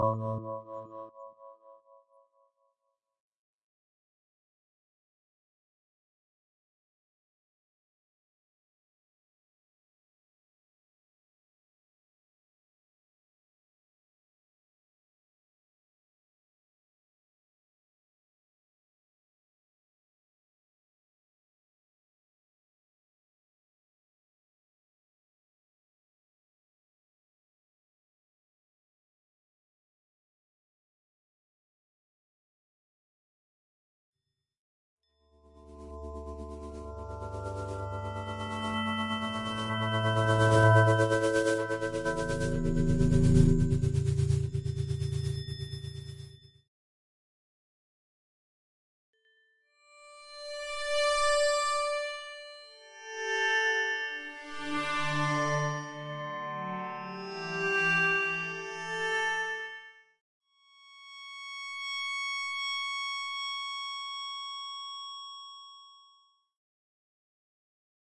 双耳打鼓 " 磷酸镁 3分钟
SchüsslerSalt博士有助于激活愈合过程，从而使身体恢复和稳定健康。 至于元素的频率，据说镁有384赫兹，磷酸盐480赫兹。 在这里，我分享来自第7号的声音。（8）磷酸镁 Mag Phos作为双耳节拍产生的软件大胆与你。
声道立体声